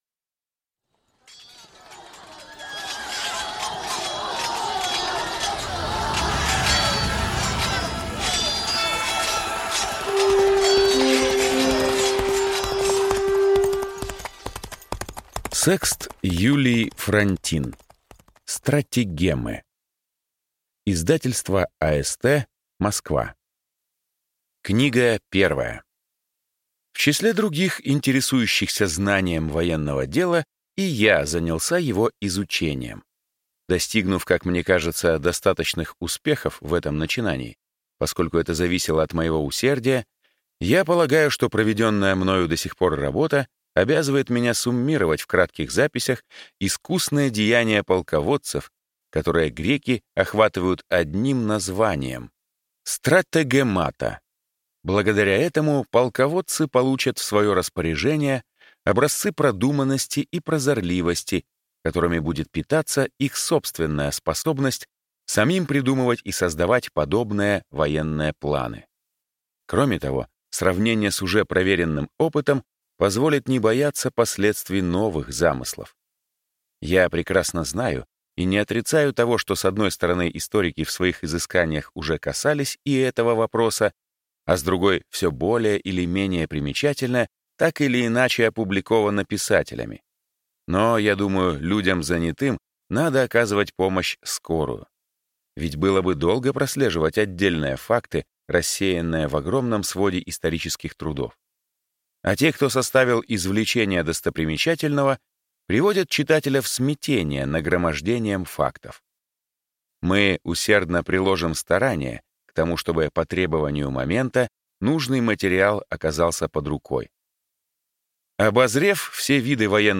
Аудиокнига Стратегемы | Библиотека аудиокниг